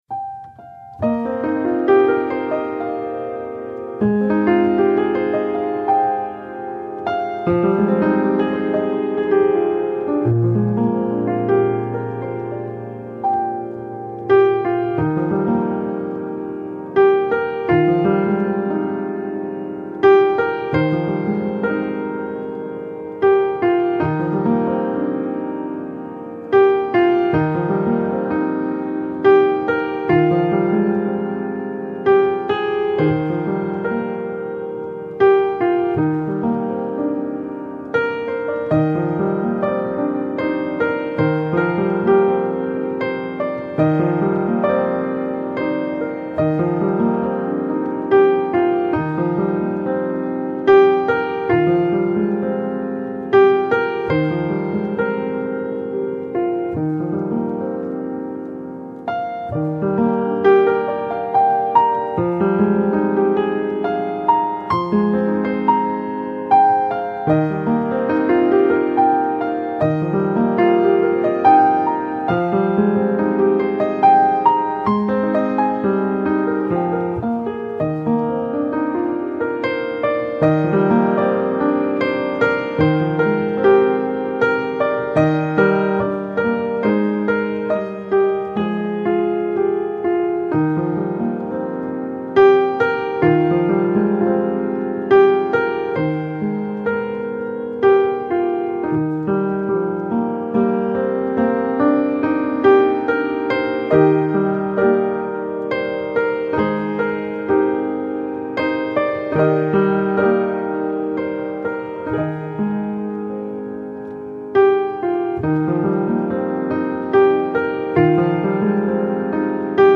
Mainly Piano
Easy Listening